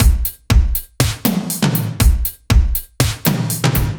Index of /musicradar/french-house-chillout-samples/120bpm/Beats
FHC_BeatB_120-03.wav